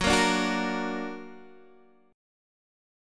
Gb+ Chord
Listen to Gb+ strummed